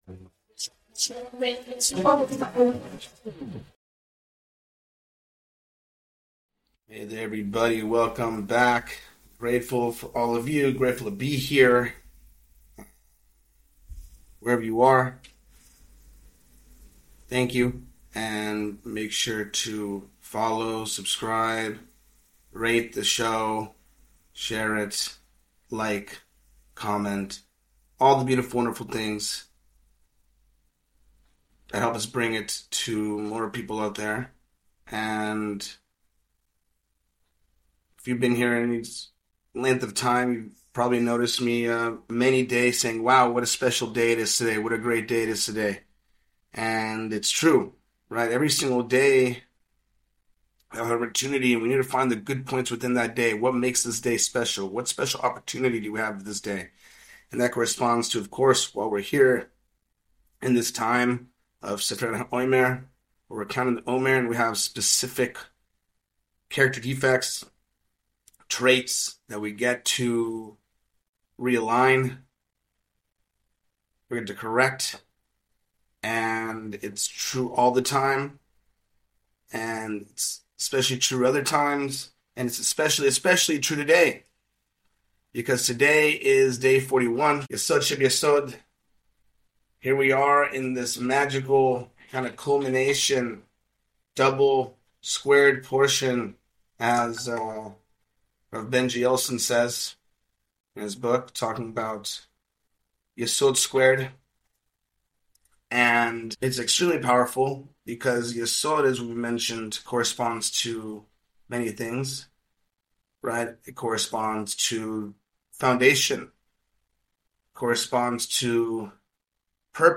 In this shiur